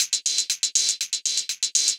Index of /musicradar/ultimate-hihat-samples/120bpm
UHH_ElectroHatC_120-02.wav